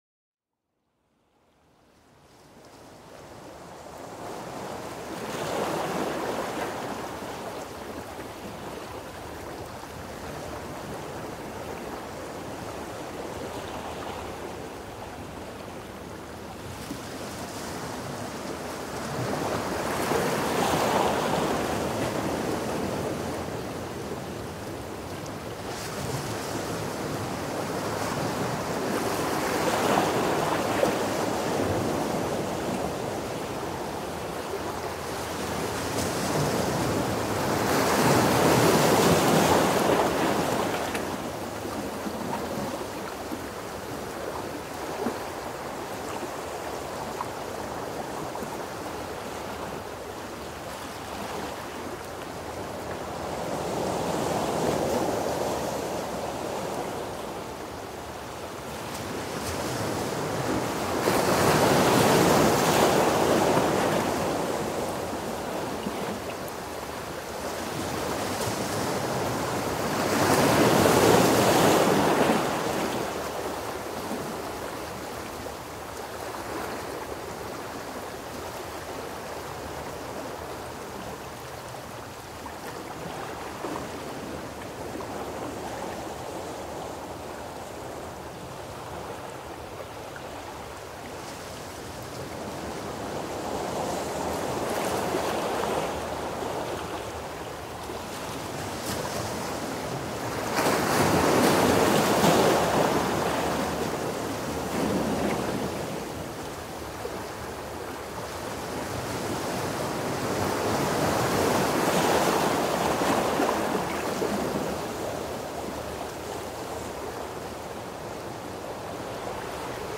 MUSIQUE RELAXANTE, EFFETS SONORES DE LA NATURE